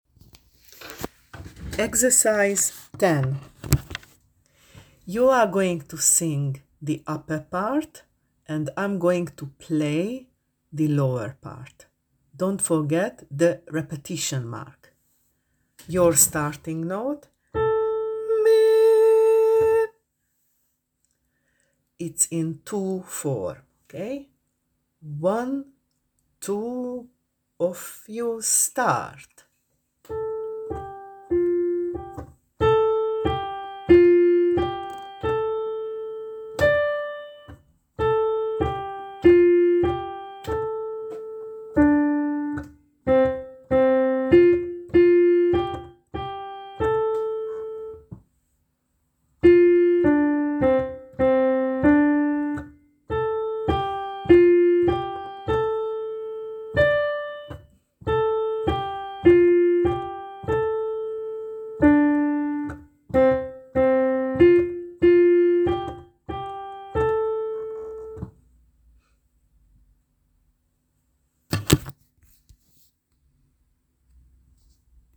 Part work, polyphony: